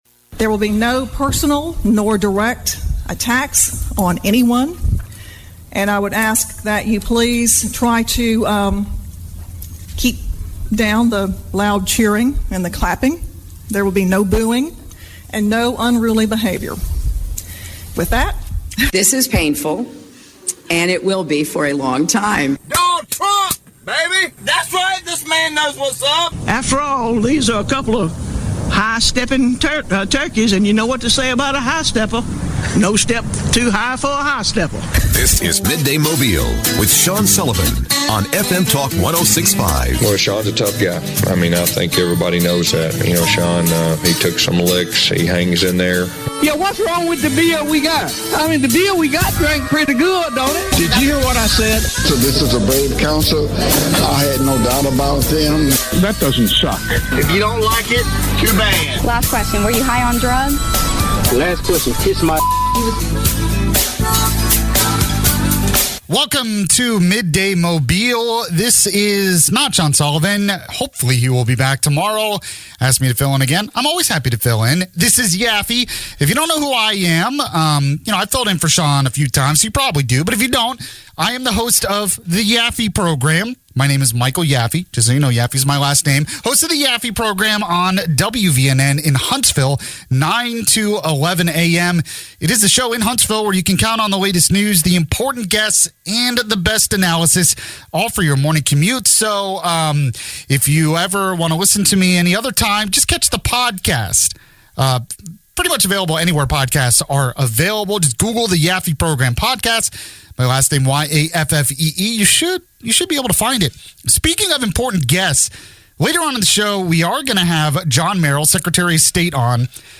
an interview with John Merrill on alleged voter fraud